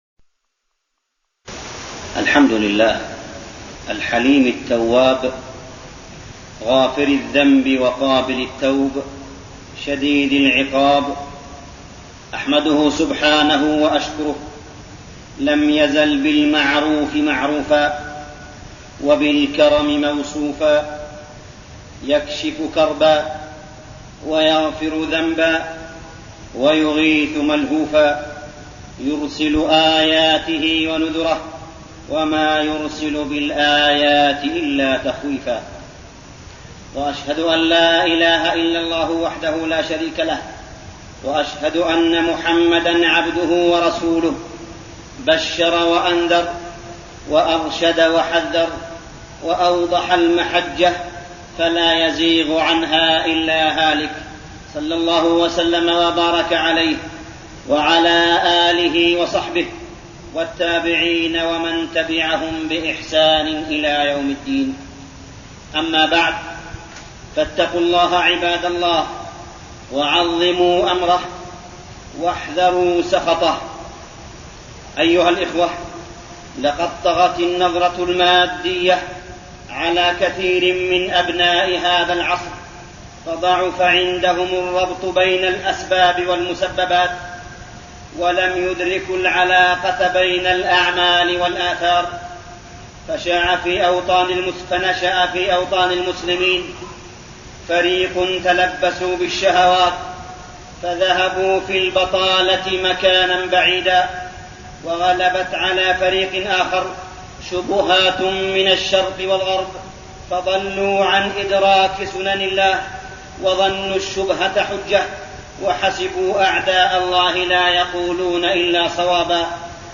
خطبة الجمعة 3-5-1410هـ > خطب الحرم المكي عام 1410 🕋 > خطب الحرم المكي 🕋 > المزيد - تلاوات الحرمين